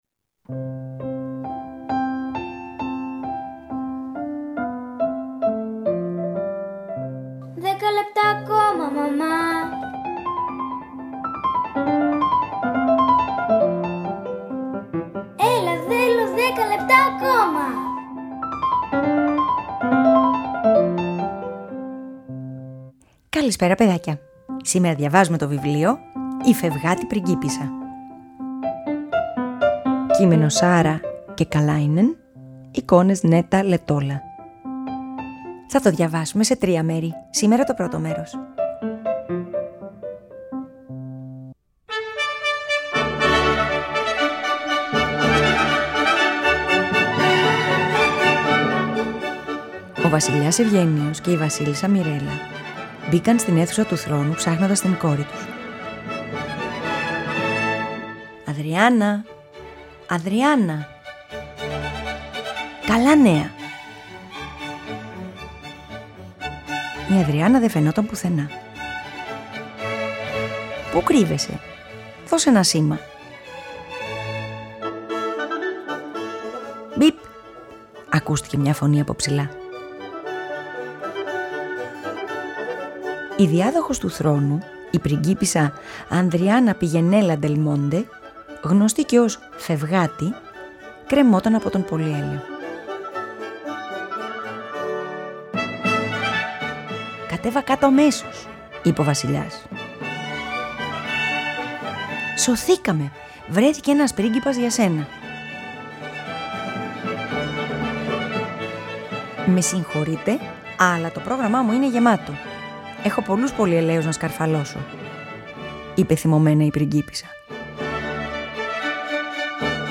ΠΑΡΑΜΥΘΙΑ